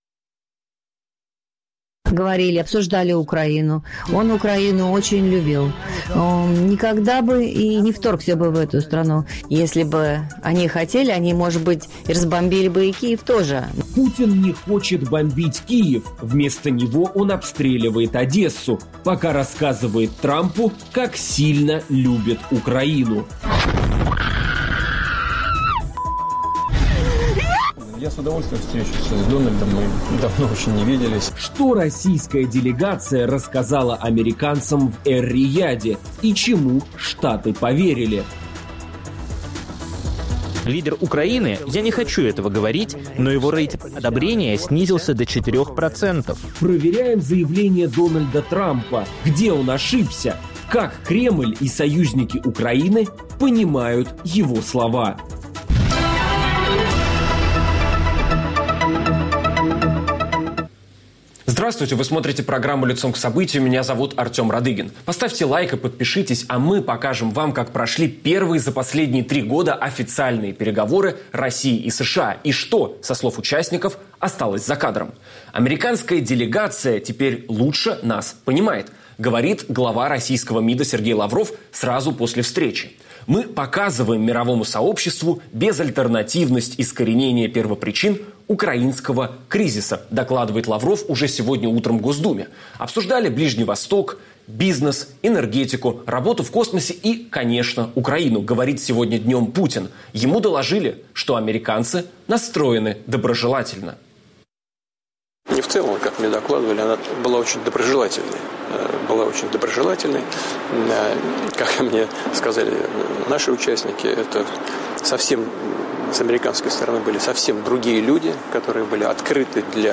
Последствия переговоров в Эр-Рияде, разногласия между странами, причастными к возможному процессу урегулирования, и перспективы поддержки Украины ее союзниками обсуждаем с политологами